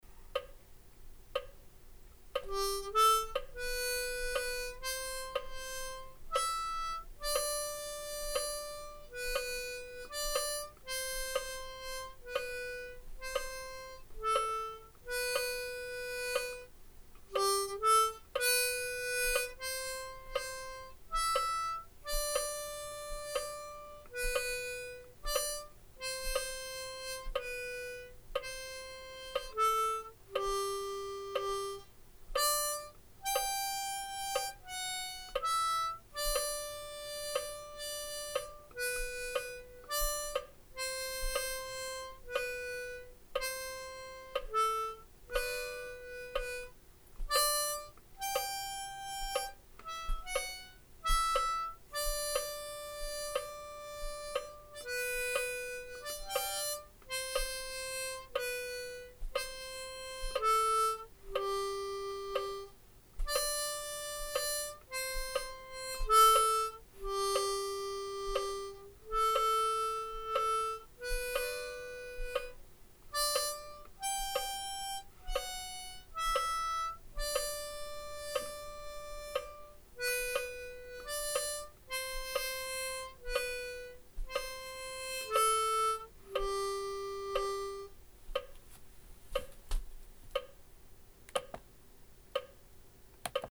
(slower, with a metronome)
The hardest part is those pesky 1.5 beat - notes